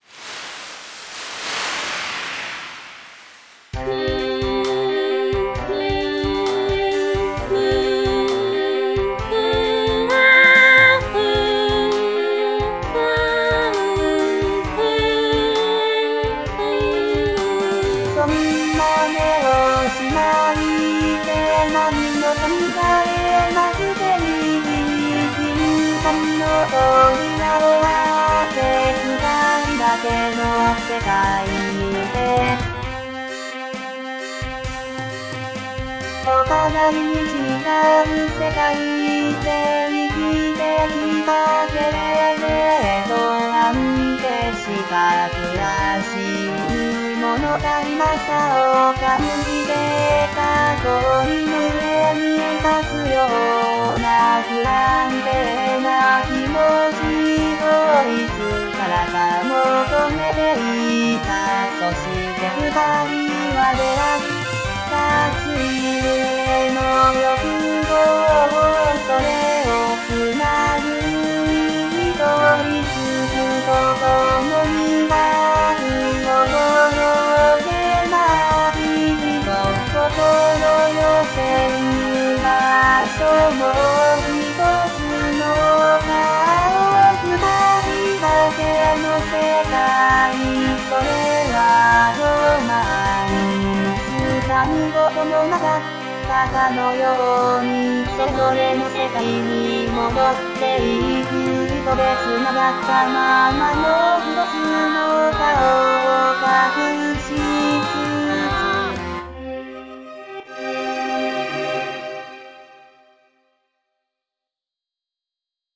MPEG ADTS, layer III, v2, 128 kbps, 16 kHz, Monaural
女と男